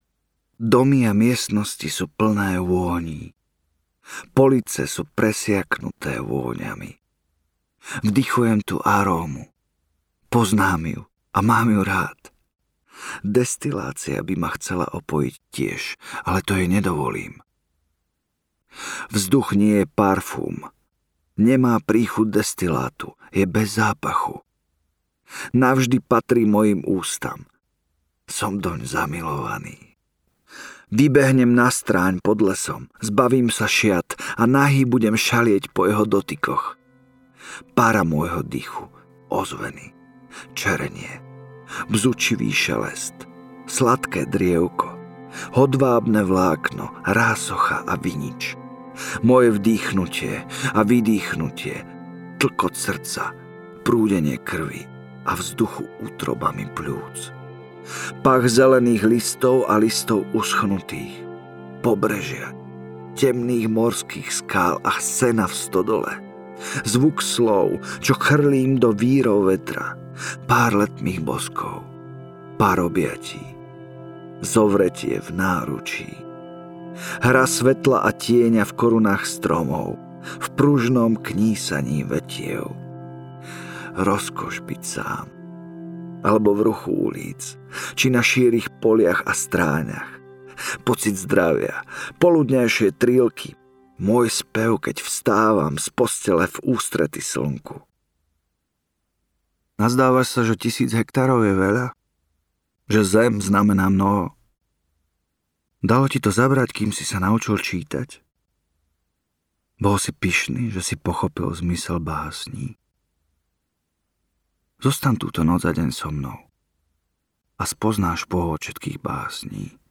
Obálka audioknihy Spev o mne